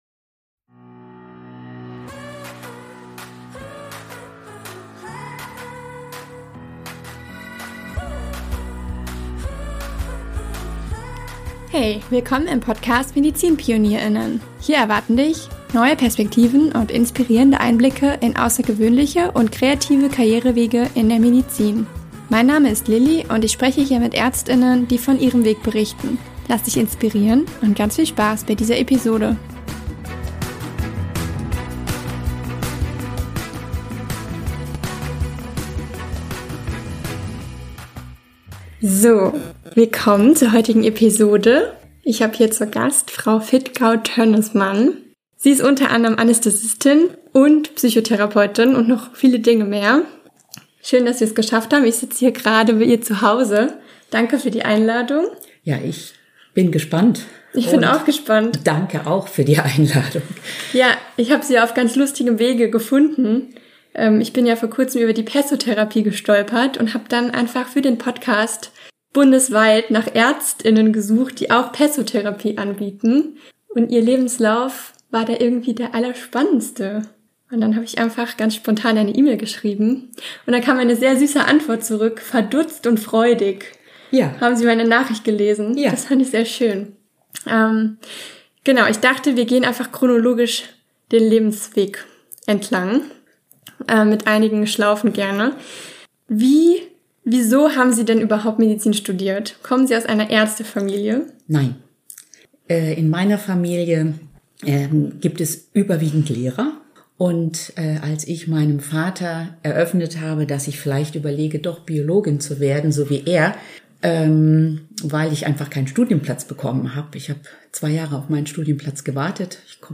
Ihr werdet in diesem Interview erfahren: 🩺 Wie man sich immer wieder neu erfindet 🩺 Wie das Curriculum der Palliativmedizin zustande kam 🩺 Was die Pesso Therapie ist 🩺 Wie man als Arzt/Ärztin als Psychotherapeut*in arbeiten und abrechnen kann